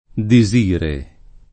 desire [de@&re] o disire [